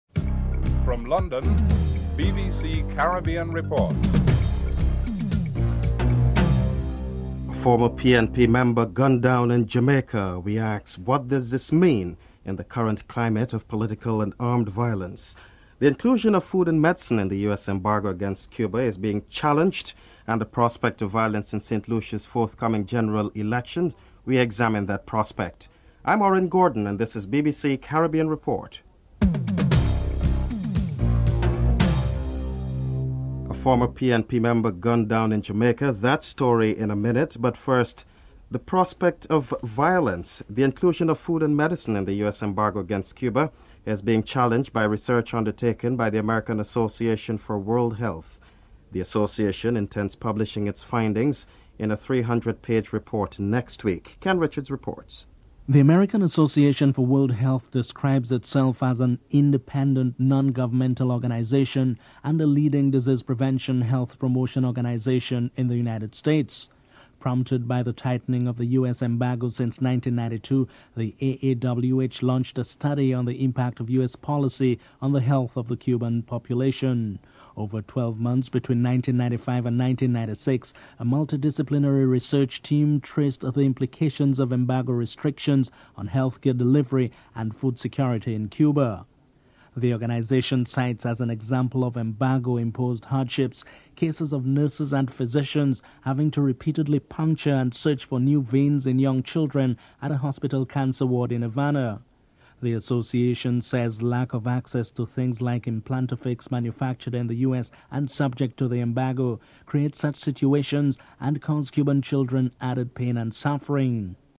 anchor
correspondent
dc.contributor.authorAnthony, Kenny (interviewee)
Prime Minister of Trinidad and Tobago, Basdeo Panday is interviewed.